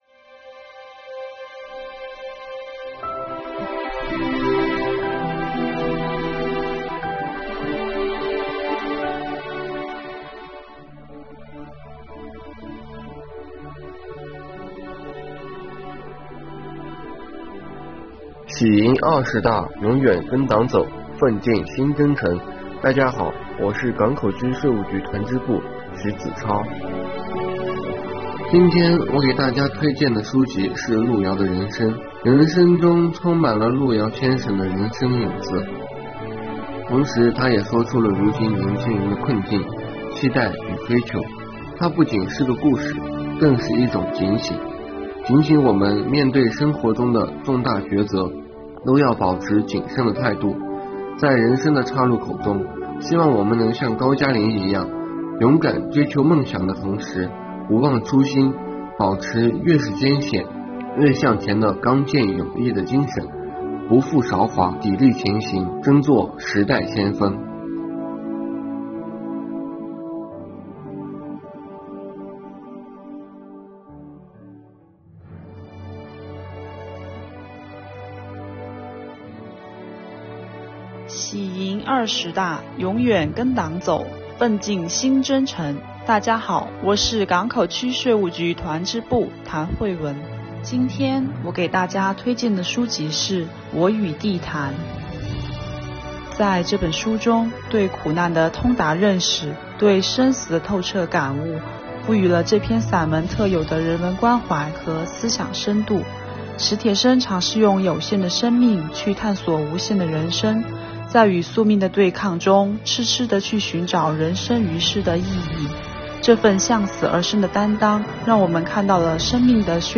下面由防城港市港口区税务局优秀青年代表带领大家经典“云共读”吧！